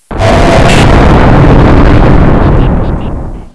Quiets down the original sounds, delays them a bit, and adds a bunch of explosions, giving the impression of a delay in the sounds reaching the player.
I don't know how to get the explosions louder without clipping. 2024-01-06 08:52:56 -08:00 77 KiB Raw Permalink History